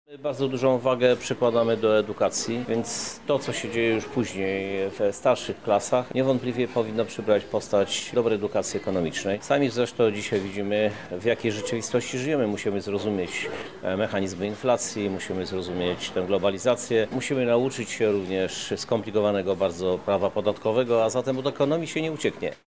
-mówi prezydent Lublina Krzysztof Żuk.